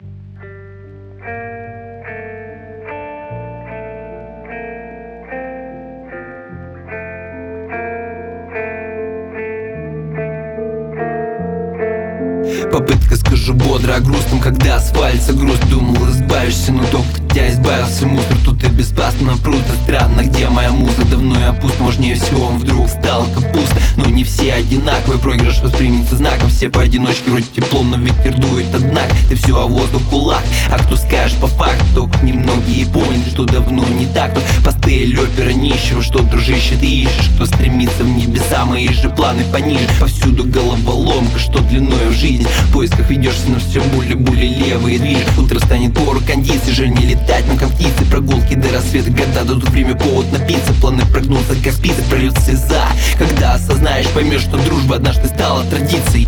Сетап: Rode k2 -> DBX 286s -> RME fireface UCX. В преобладающем большинстве запись рэпа старой школы.